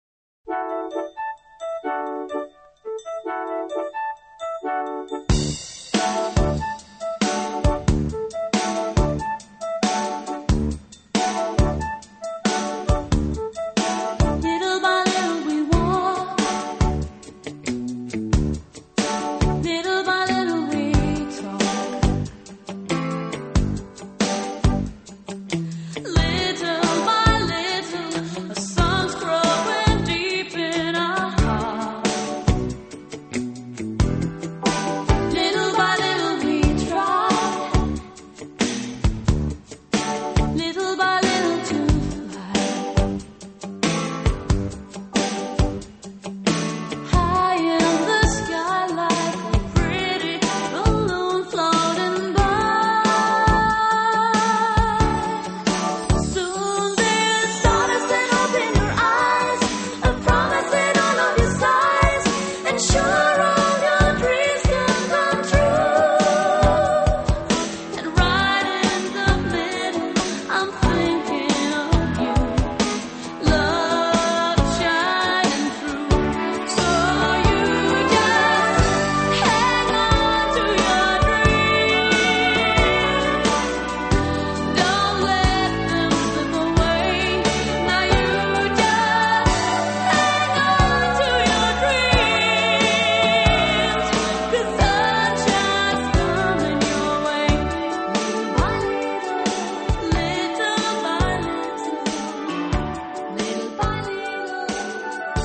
スウィング・ポップを歌うノルウェーの女性デュオが
ベースはあくまでもスウィング系のリズムをポップス調で歌うグループですが、 中にはＡＯＲ度の高い楽曲も含まれております。